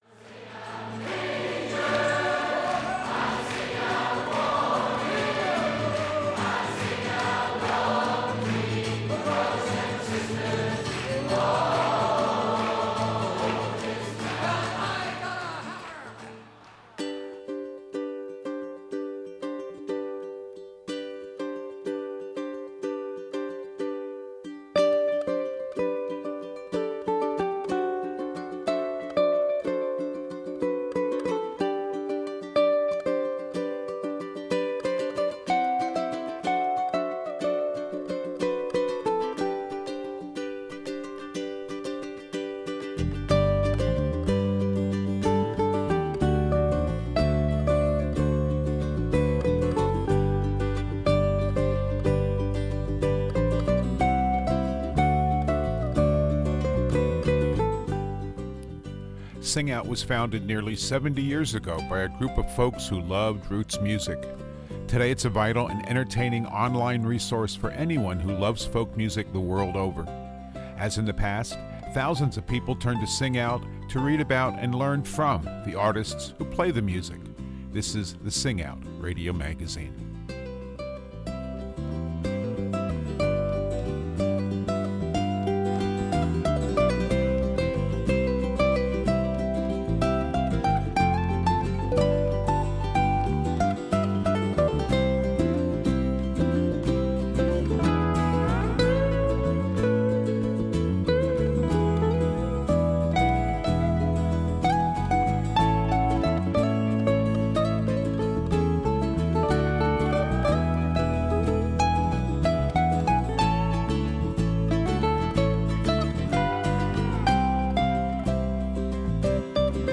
This week on the program we'll feature music on the ukulele. It's a handy instrument that's accessible yet works for many ability levels and different kinds of music, too.